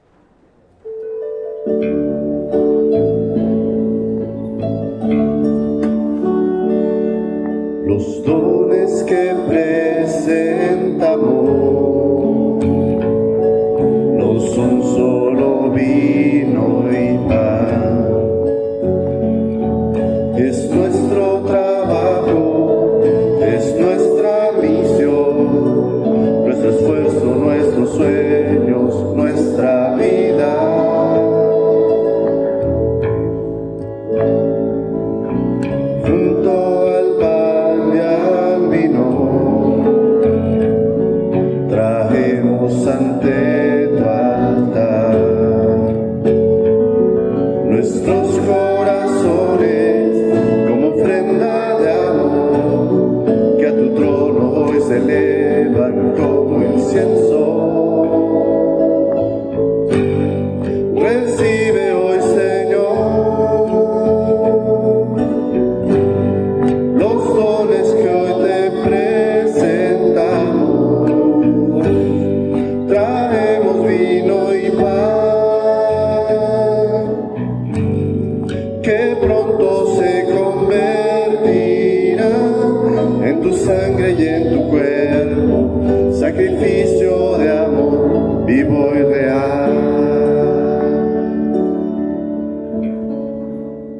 Coro Nazir
Tempo 80
Ritmo Pop